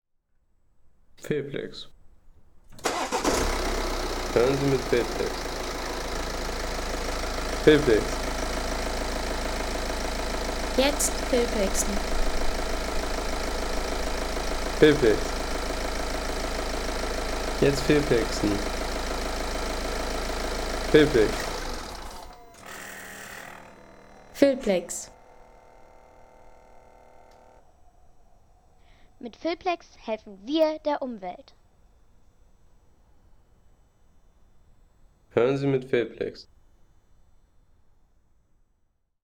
Klang eines BMW X3 Dieselmotors – Start, Leerlauf und Abstellen.